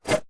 Scifi Screen UI 4.wav